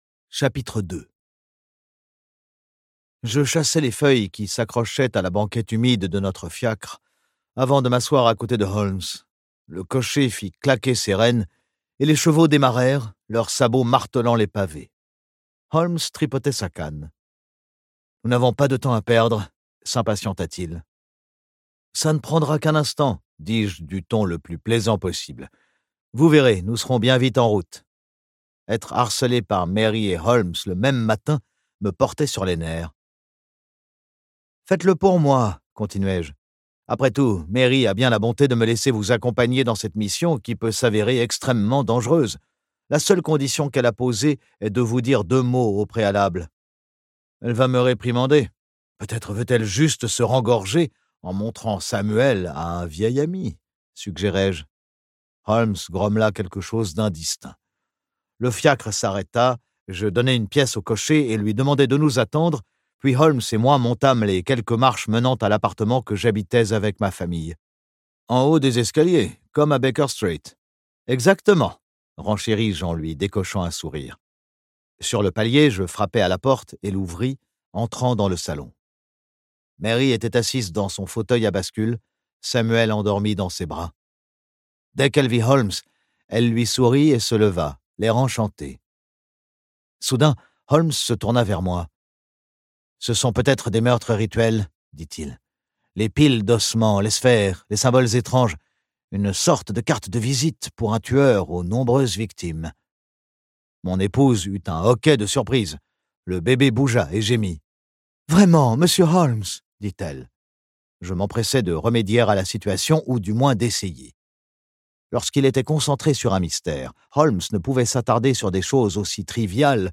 Et pour cause : que reste-t-il une fois qu'on a tout éliminé, y compris l'improbable ?Ce livre audio est interprété par une voix humaine, dans le respect des engagements d'Hardigan.